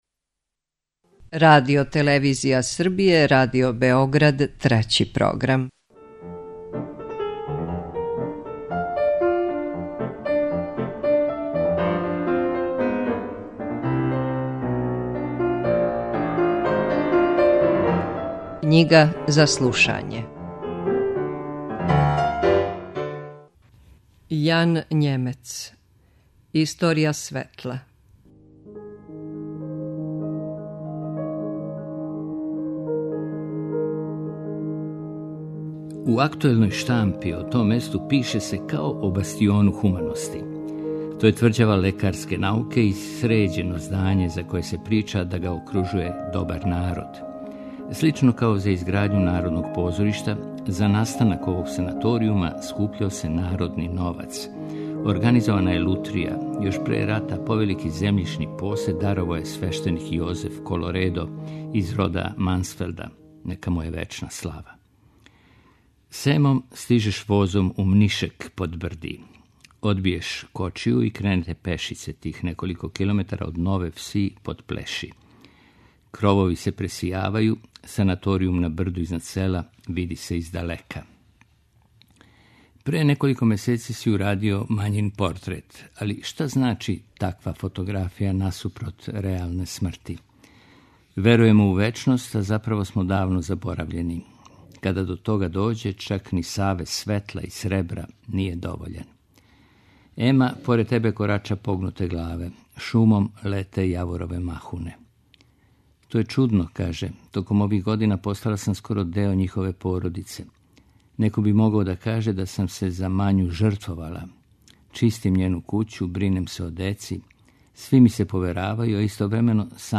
преузми : 8.43 MB Књига за слушање Autor: Трећи програм Циклус „Књига за слушање” на програму је сваког дана, од 23.45 сати.